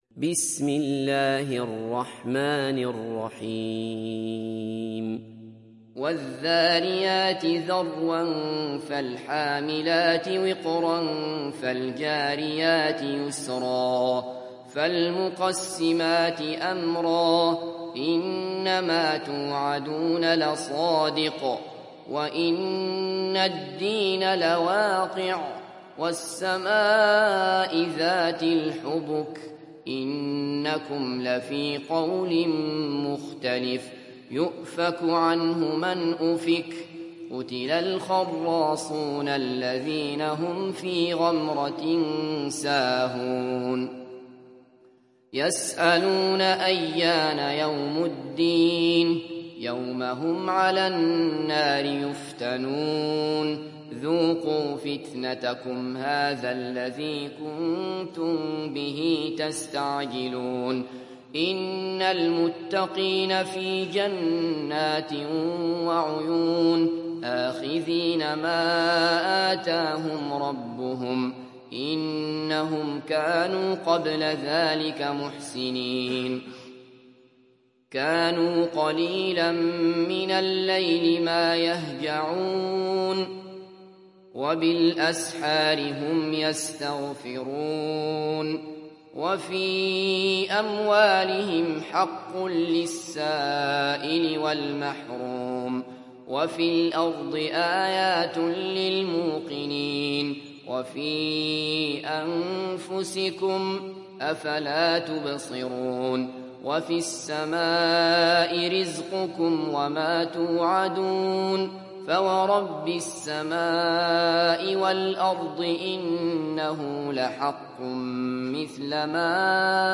تحميل سورة الذاريات mp3 بصوت عبد الله بصفر برواية حفص عن عاصم, تحميل استماع القرآن الكريم على الجوال mp3 كاملا بروابط مباشرة وسريعة